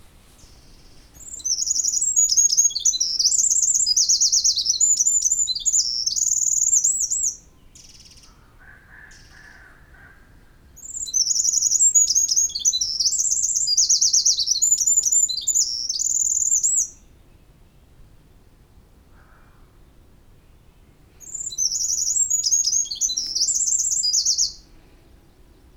Eurasian Wren
wren-eurasian001-Troglodytes-troglodytes.wav